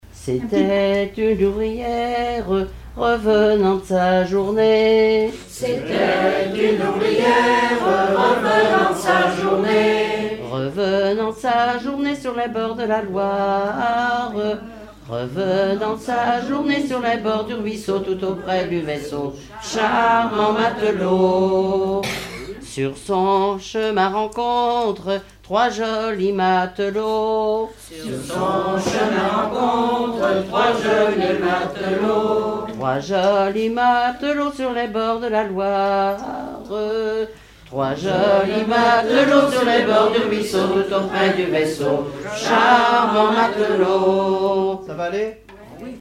Mémoires et Patrimoines vivants - RaddO est une base de données d'archives iconographiques et sonores.
Genre strophique
Répertoire de chansons populaires et traditionnelles
Pièce musicale inédite